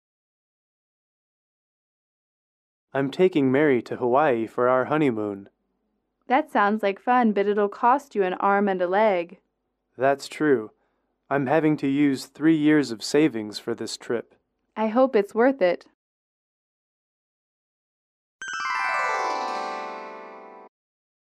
英语口语情景短对话28-1：度蜜月(MP3)